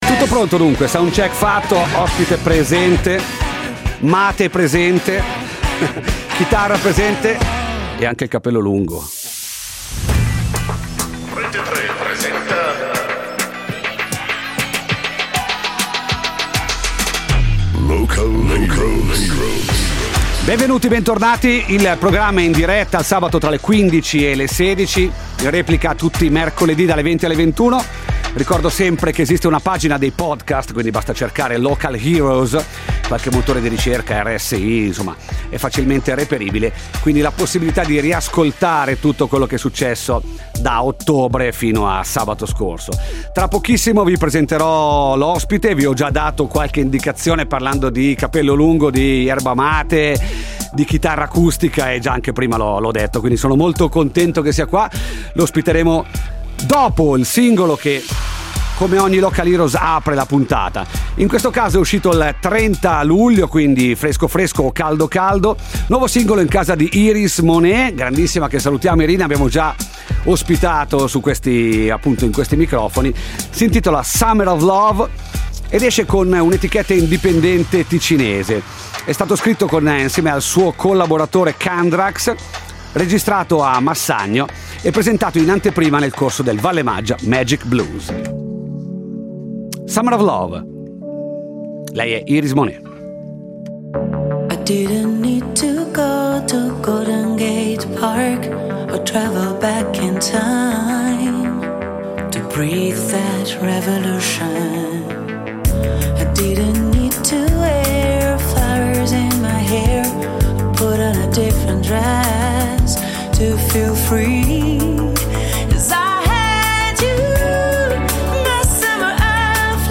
musica Live!